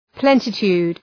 {‘plenə,tu:d}